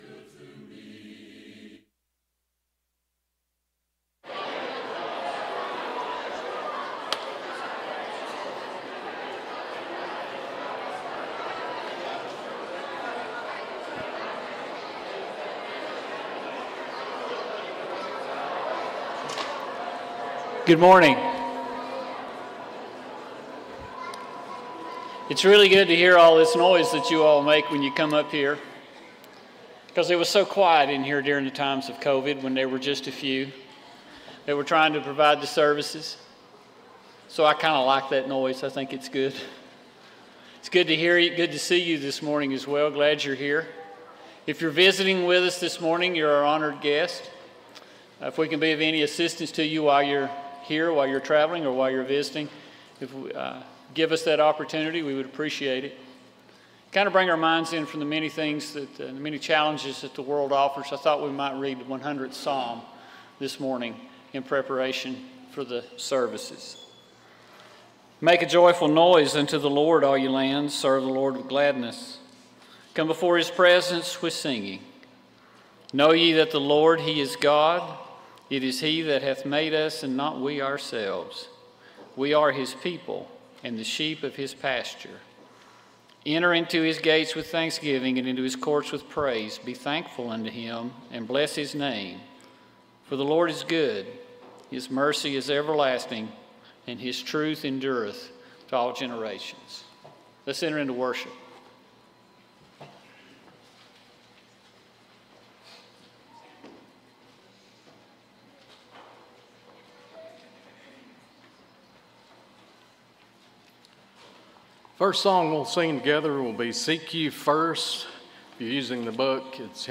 Proverbs 4:23 , English Standard Version Series: Sunday AM Service